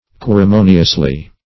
querimoniously - definition of querimoniously - synonyms, pronunciation, spelling from Free Dictionary